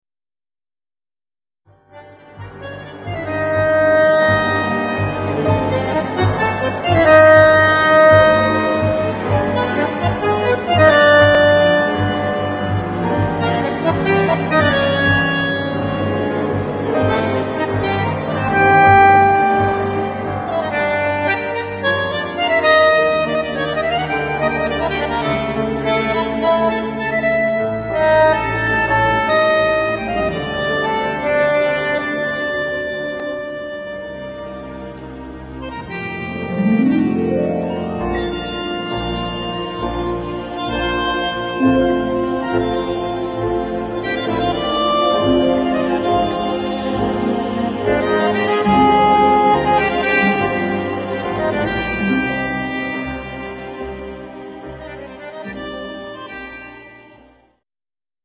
Argentijnse Tango
bandoneon